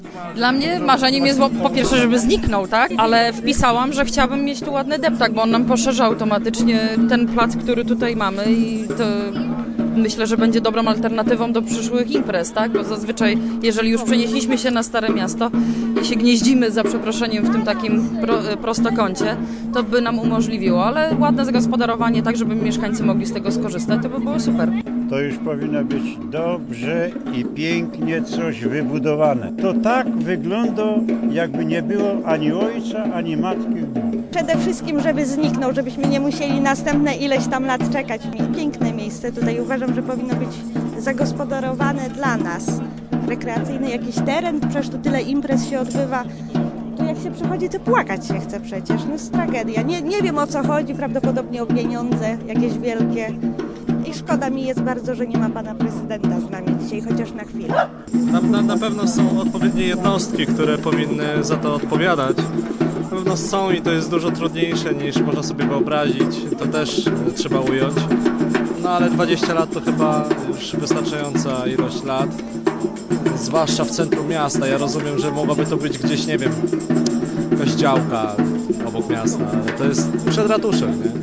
Co wpisali? O to pytaliśmy w trakcie imprezy:
Posłuchaj opinii mieszkańców: